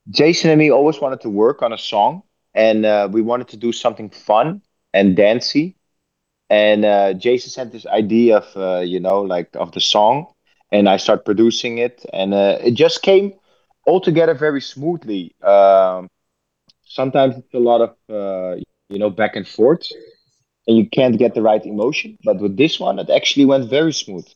Tom smo prilikom intervjuirali R3HAB-a koji je oduševljen suradnjom s Jasonom Derulom.